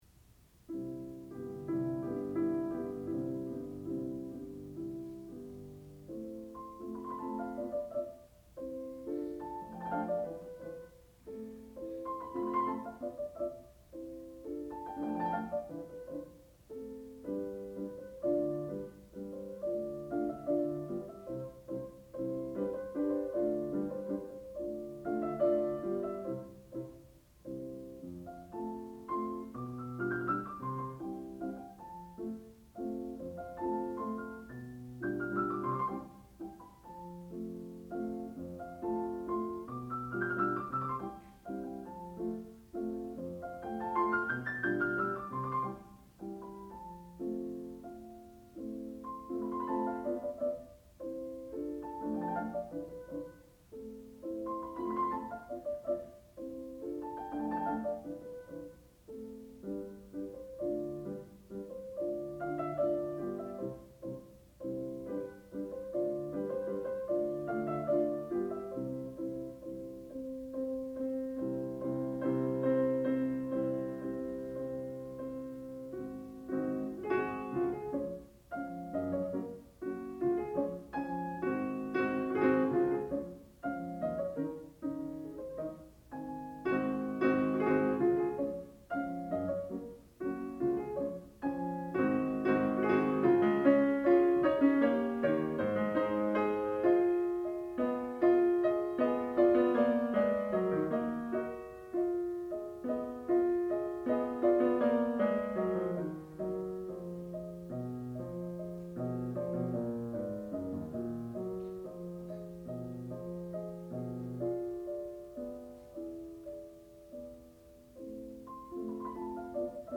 sound recording-musical
classical music
Eva Knardahl, piano (performer).